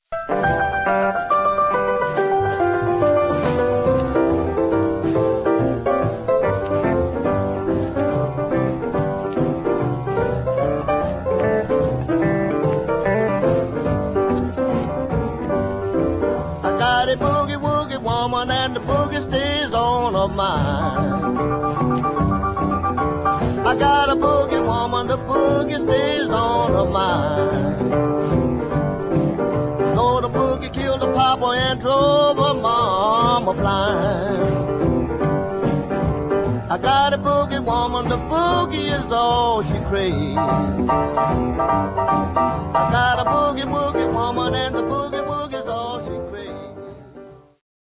Condition Some surface noise/wear Stereo/mono Mono
Rythm and Blues